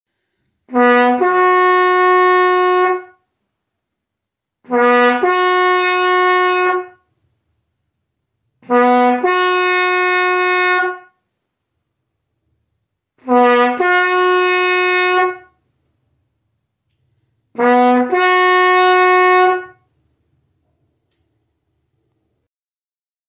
CLICK BELOW TO HEAR A SAMPLING OF ACTUAL BUGLE CALLS
USED BY AMERICAN CYCLING CLUBS OF THE 1880'S-90'S